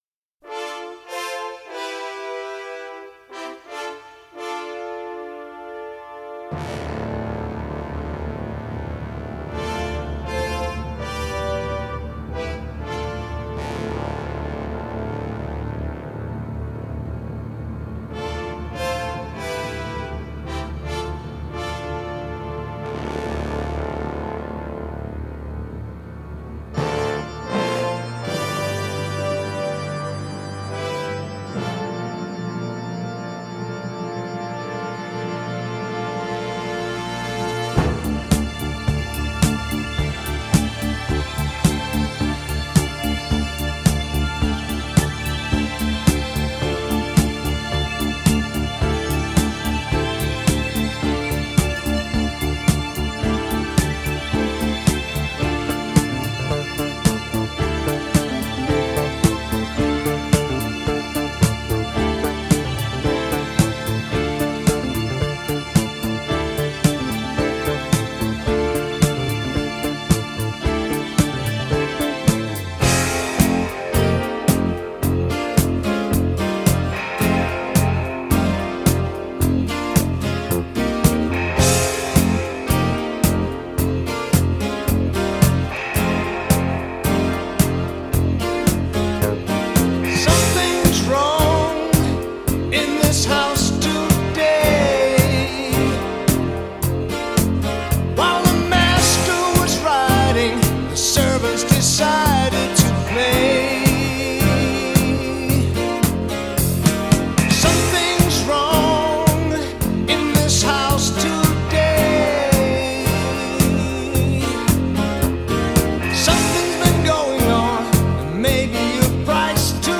Progressive Rock / Art Rock